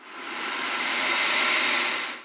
c_croc_atk1.wav